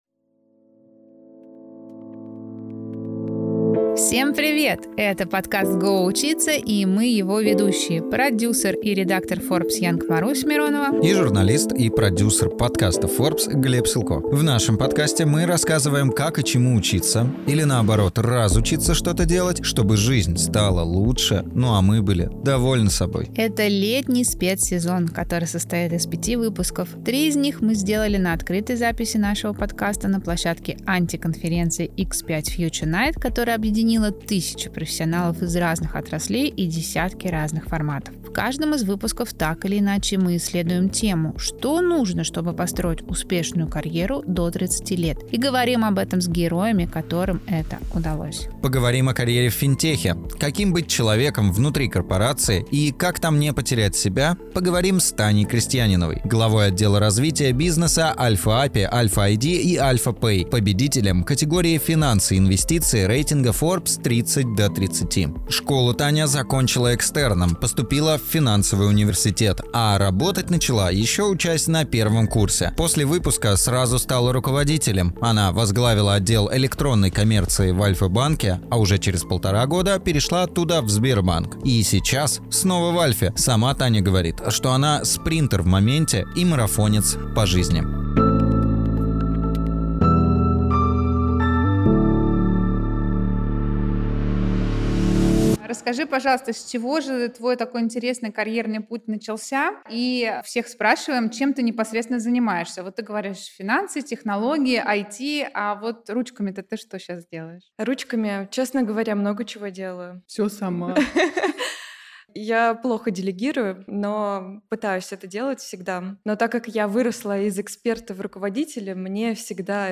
Три выпуска для него были сделаны на открытой записи на площадке антиконференции X5 Future Night , которая объединила тысячи профессионалов из разных отраслей и десятки разных форматов.